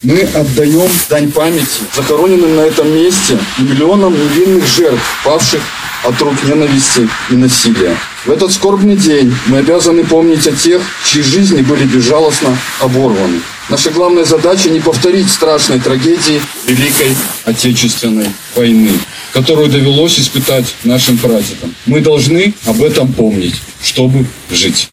В мемориальном комплексе на могиле жертв фашистского террора — узникам Барановичского гетто на улице Чернышевского состоялся митинг — реквием, посвящённый Международному дню памяти жертв Холокоста.
Было уничтожено 13 тысяч узников, — сказал,  обращаясь к присутствующим,  заместитель председателя горисполкома Вадим Щербаков.